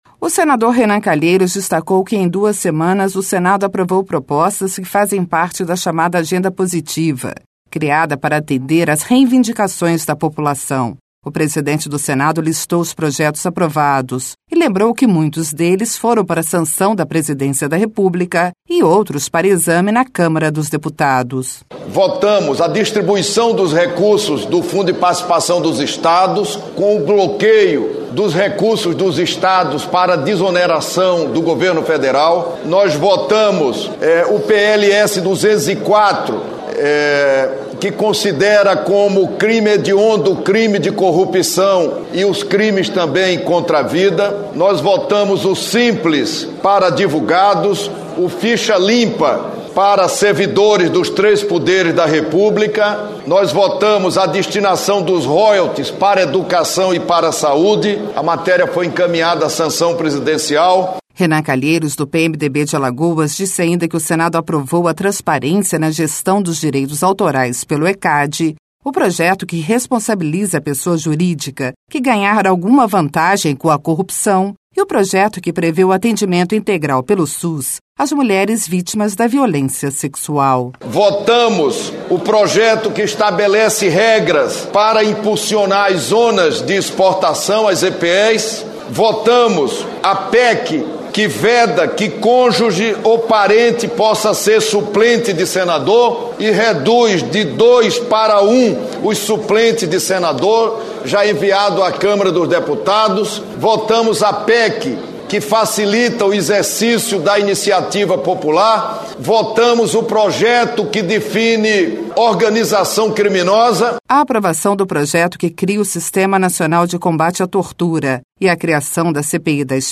Rádio Senado
LOC: O PRESIDENTE DO SENADO, RENAN CALHEIROS, DO PMDB DE ALAGOAS, FEZ UM BALANÇO DOS TRABALHOS DO SENADO NOS ÚLTIMOS QUINZE DIAS.